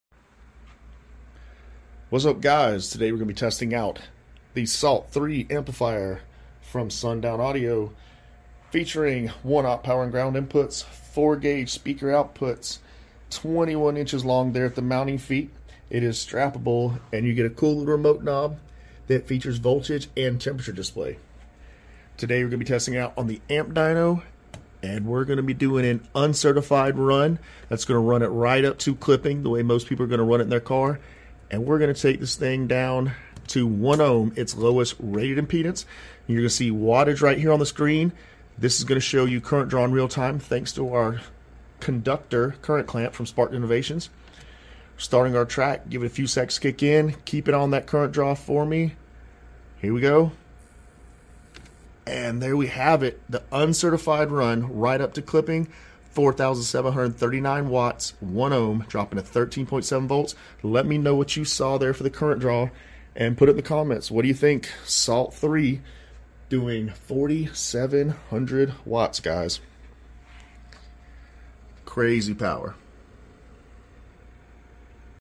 Sundown Audio Salt-3 uncertified 1ohm amp dyno test. car audio bass sound subwoofer amplifier videos